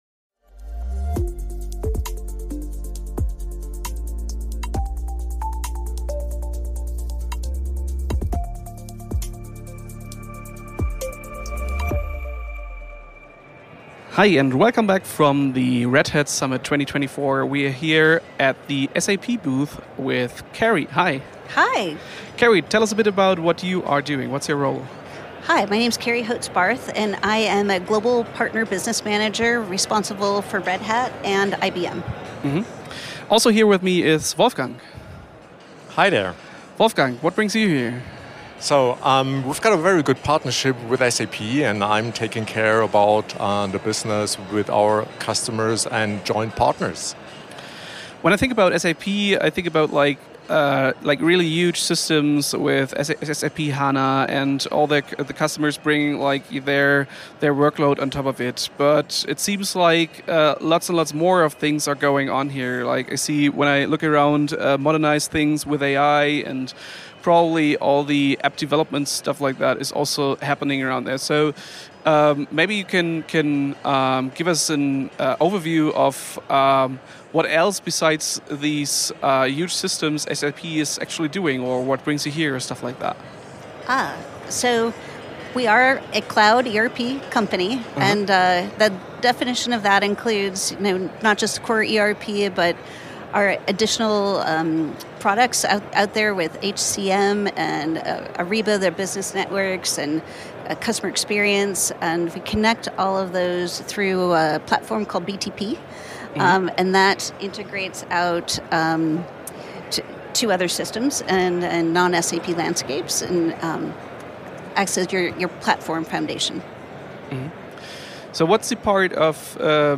Red Hat Summit in Denver.